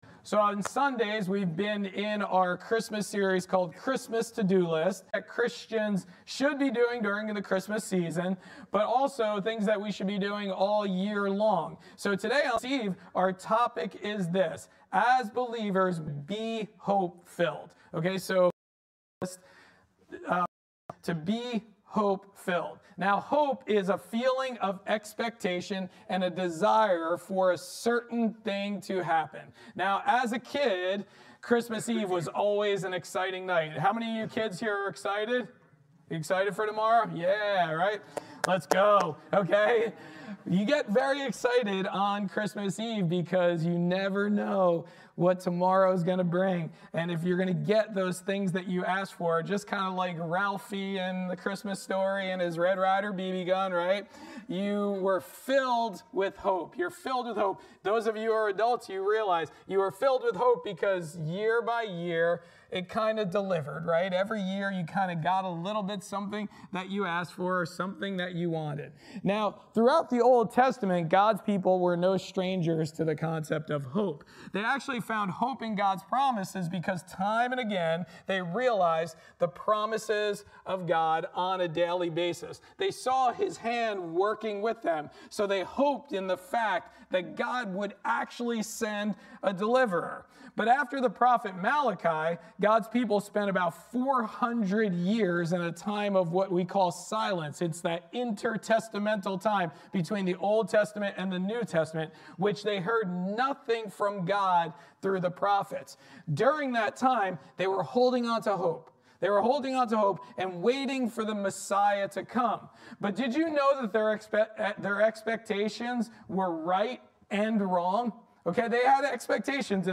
In this Christmas Eve message, we learn about being filled with hope.